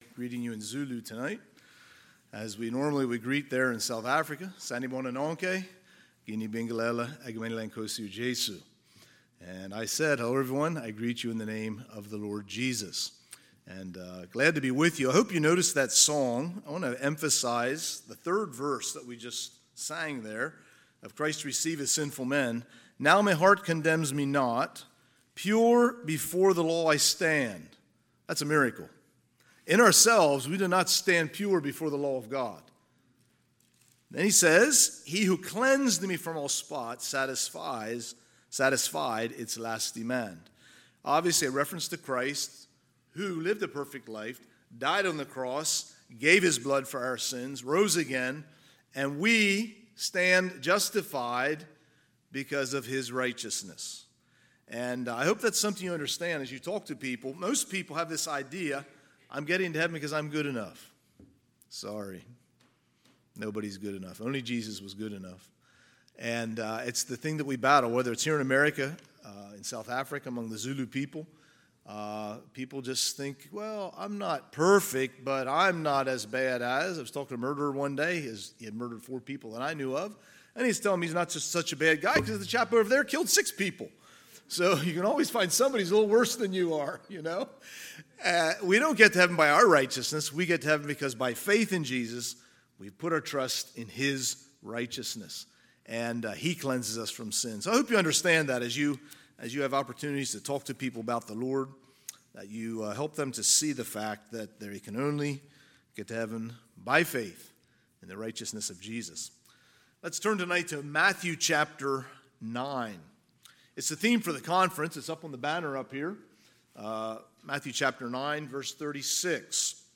Missions Conference , Sermons